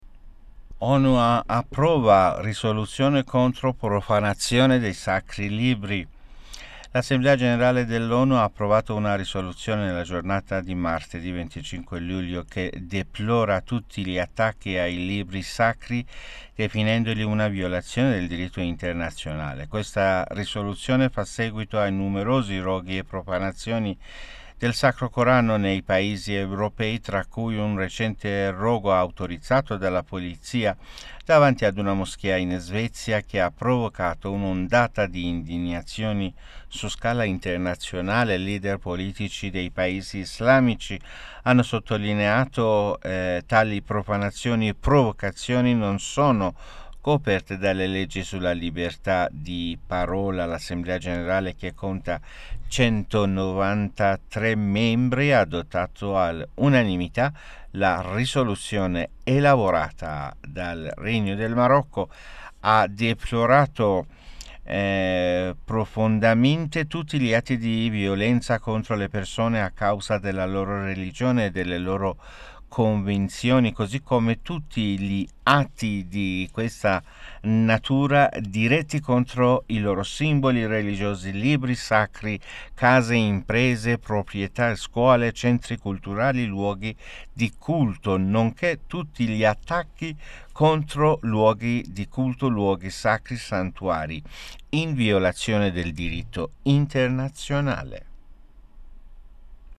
Notiziario